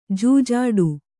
♪ jūjāḍu